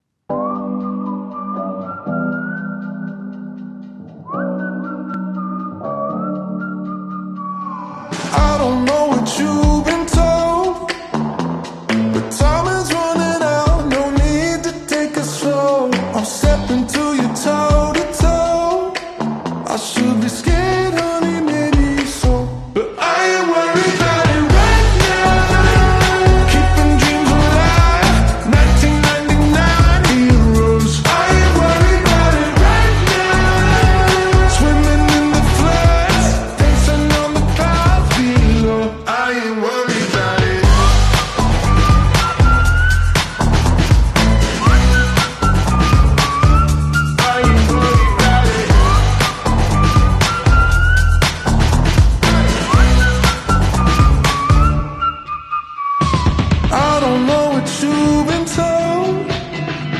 Best jet fly by sounds sound effects free download
Best jet fly by sounds in WarThunder!